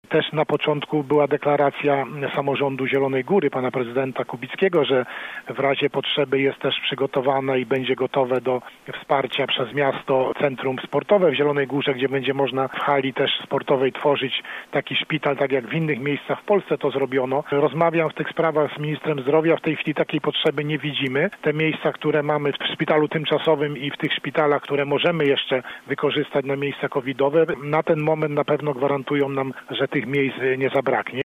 Władysław Dajczak był gościem Rozmowy po 9.